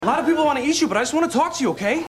Tags: SNL Andy Samberg Mark Walhberg Mark Walhberg impersonation Mark Wahlberg Talks to Animals